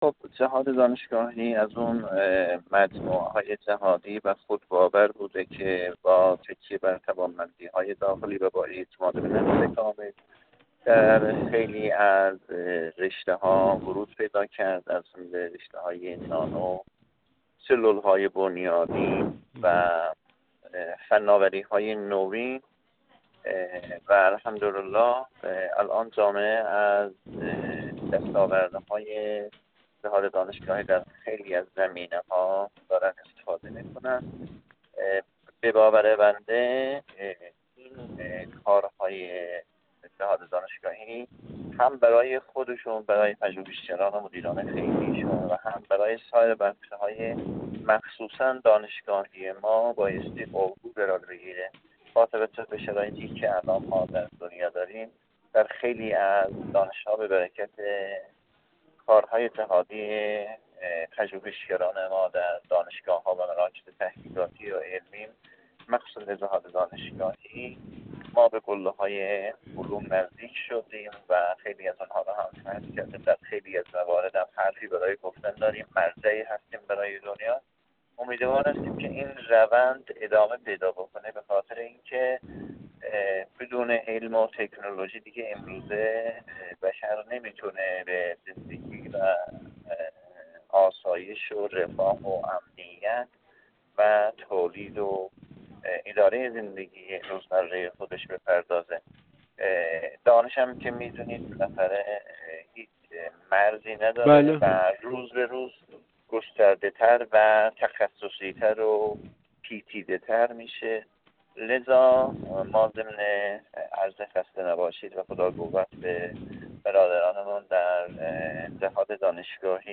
الله‌ویردی دهقانی، دبیر اول کمیسیون صنایع و معادن مجلس
الله‌ویردی دهقانی، دبیر اول کمیسیون صنایع و معادن مجلس شورای اسلامی، در گفت‌وگو با ایکنا درباره نقش فعالیت‌های جهاددانشگاهی در افزایش امید به آینده و تقویت روحیه خودباوری ملی گفت: جهاددانشگاهی از مجموعه‌های خودباور و جهادی است که با تکیه بر توانمندی‌های داخلی و با اعتماد به‌نفس کامل در خیلی از حوزه‌های علمی و فناورانه مانند نانو، سلول‌های بنیادی، صنعت نفت و حرکت در مسیر استفاده از فناوری‌های نو ورود پیدا کرده و امروز جامعه از بسیاری از دستاوردهای جهاددانشگاهی در زمینه‌های مختلف استفاده می‌کند.